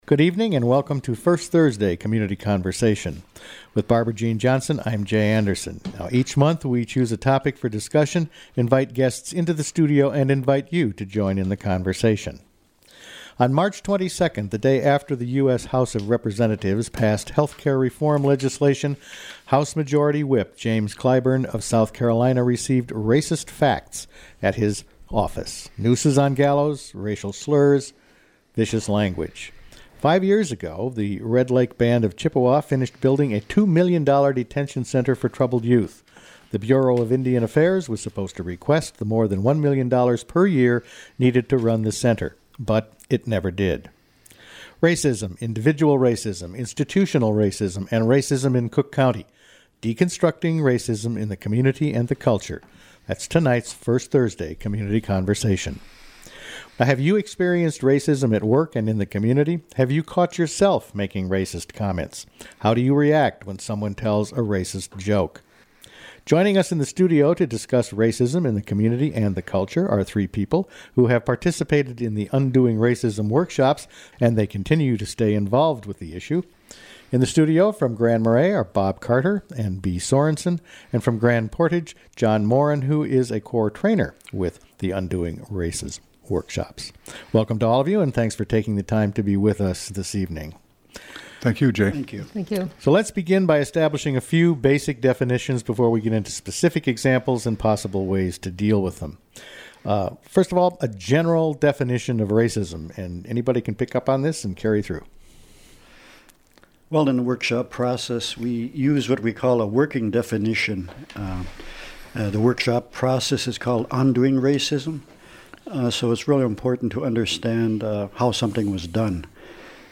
in-studio guests